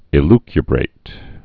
(ĭ-lky-brāt)